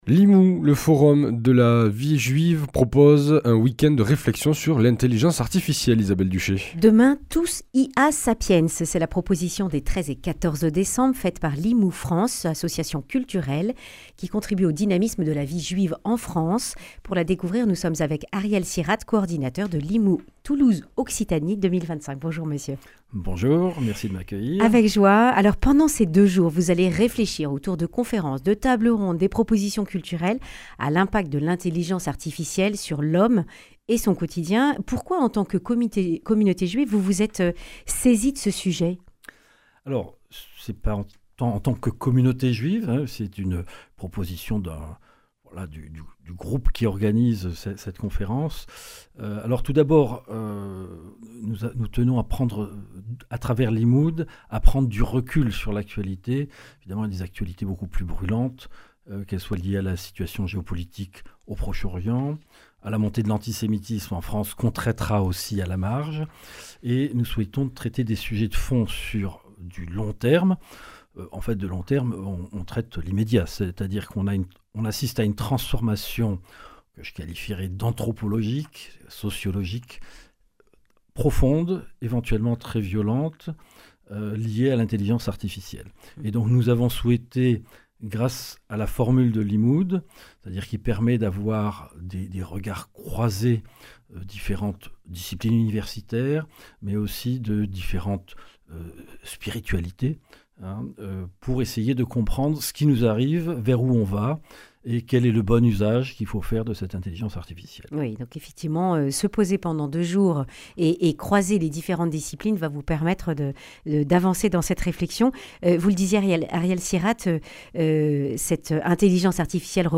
Accueil \ Emissions \ Information \ Régionale \ Le grand entretien \ Demain : tous IA-Sapiens ?